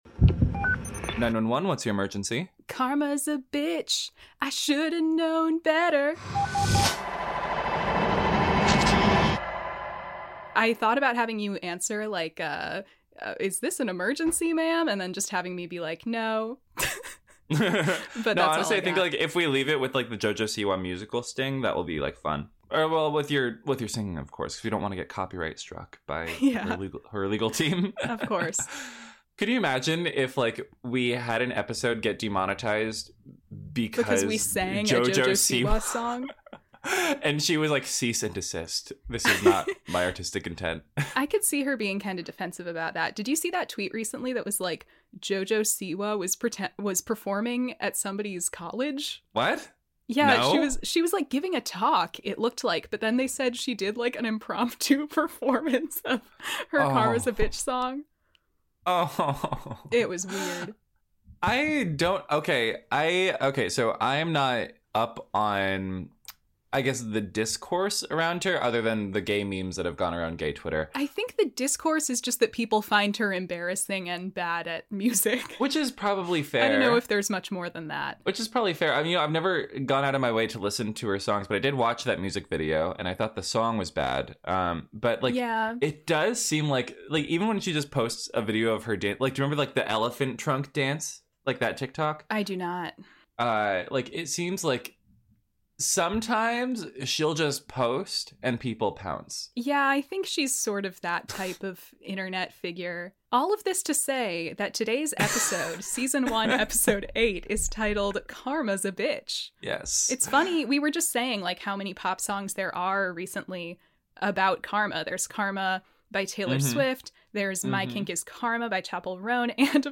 First Respodcast is a lighthearted rewatch podcast for ABC's 9-1-1.